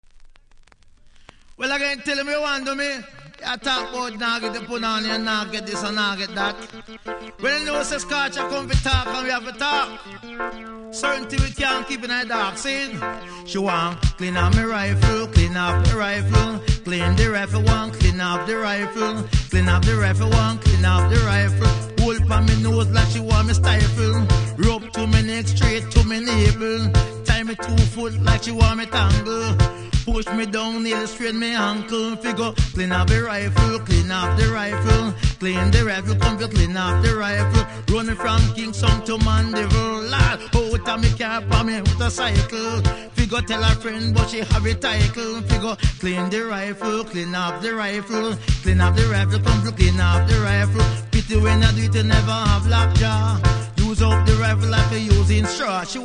REGGAE 80'S
キズそこそこありますが音は良好なので試聴で確認下さい。